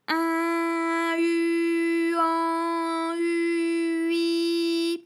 ALYS-DB-001-FRA - First, previously private, UTAU French vocal library of ALYS
in_u_an_u_ui.wav